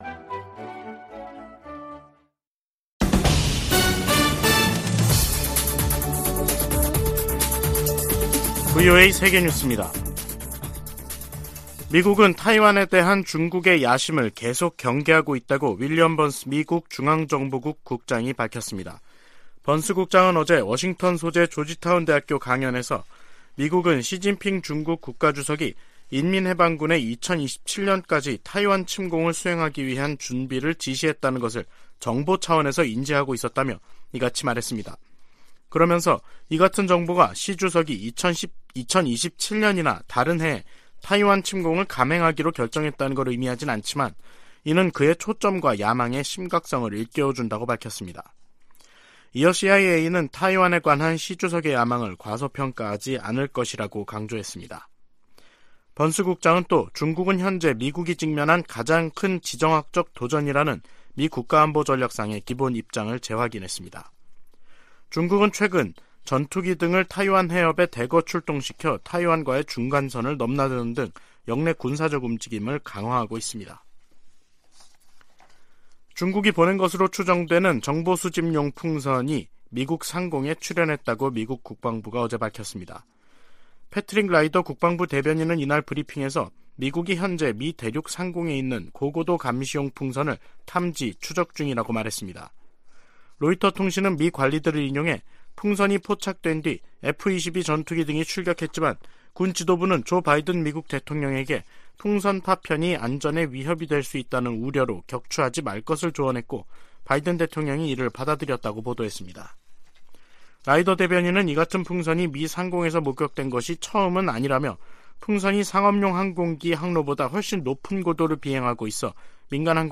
VOA 한국어 간판 뉴스 프로그램 '뉴스 투데이', 2023년 2월 3일 3부 방송입니다. 미국과 한국 외교장관들은 오늘 워싱턴에서 열리는 회담에서 북한 문제는 물론 경제와 외교 등 다양한 주제를 다룰 것이라고 밝혔습니다. 미국 백악관은 북한의 미한 연합훈련 비난에 대해 북한에 대한 적대적 의도가 없는 통상적인 훈련이라고 반박했습니다.